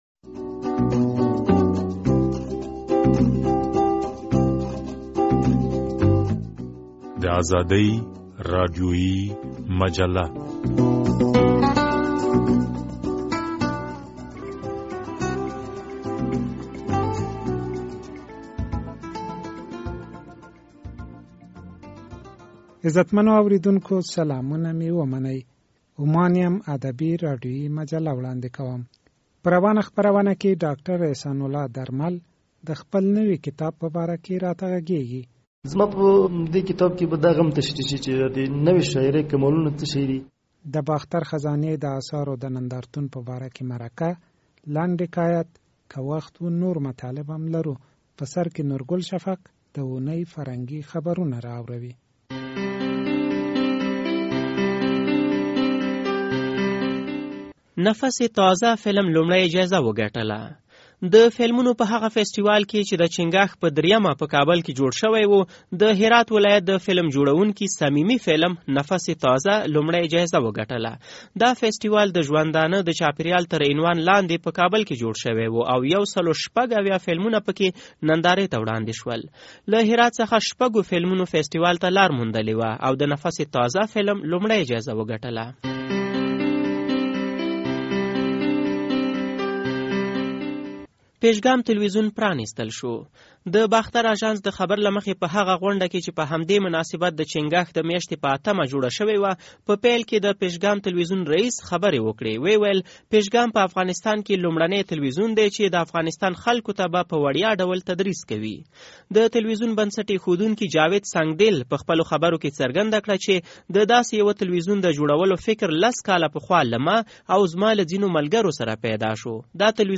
په نننۍ خپرونه کې د باختر خزانې د اثارو په باره کې مرکه اورئ